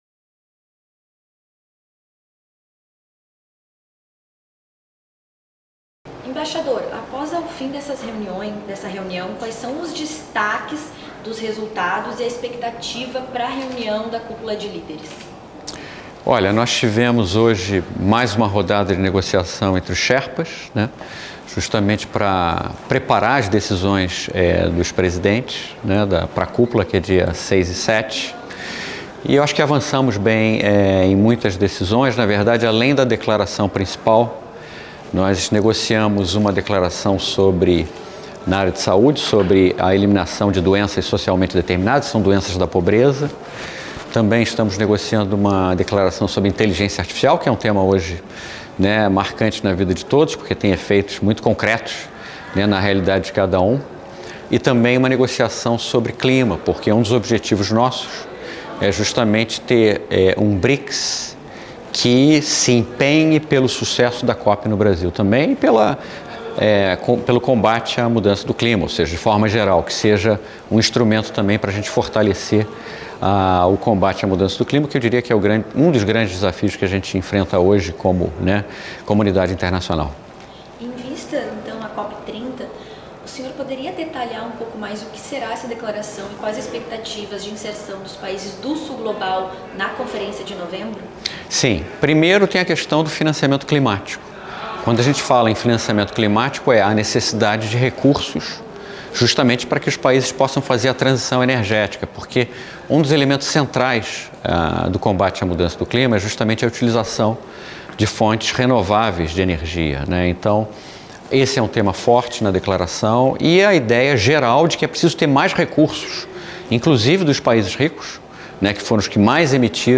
Clima, saúde e IA são destaques de entrevista exclusiva com Sherpa
Em entrevista exclusiva, Mauricio Lyrio, Sherpa da presidência do BRICS, detalhou os preparativos para a Cúpula de Líderes do BRICS, com acordos sobre financiamento climático, combate a doenças socialmente determinadas e regulamentação da Inteligência Artificial. Ele destacou ainda o esforço do Brasil para fortalecer a cooperação entre os países do Sul Global em meio a crises multilaterais.